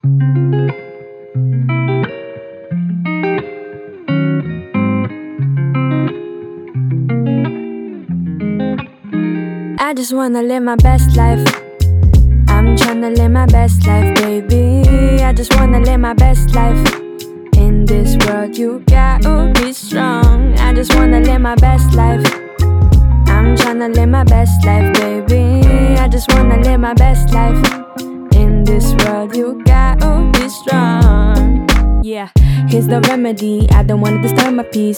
Жанр: Хип-Хоп / Рэп
Hip-Hop, Hip-Hop, Rap